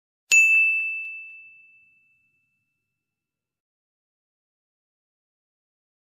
Correct sound effect
Thể loại: Hiệu ứng âm thanh
Đây là tiếng "ding!", chuông nhẹ, hoặc âm thanh vui tai, rõ ràng, mang tính xác nhận tích cực, tạo cảm giác thành công và khích lệ người chơi.
correct-sound-effect-www_tiengdong_com.mp3